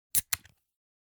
ジュースを飲む・飲み物関連の音_5（缶を開ける音）